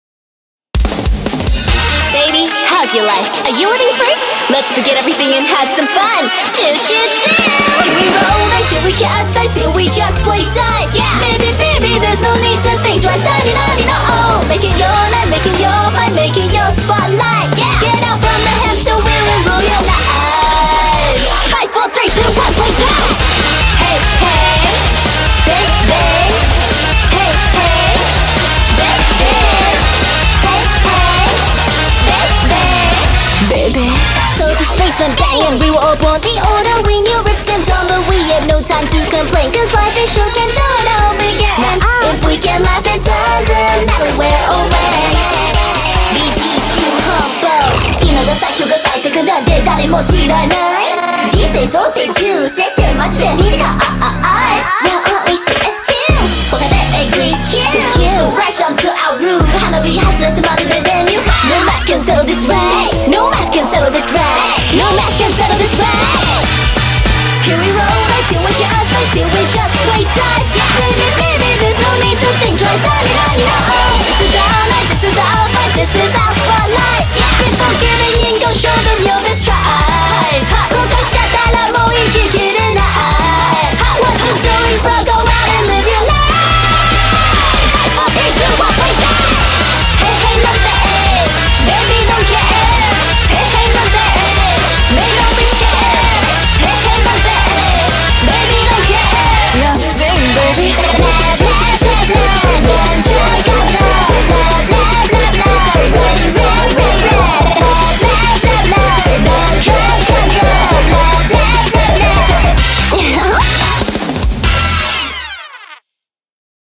BPM145
Audio QualityCut From Video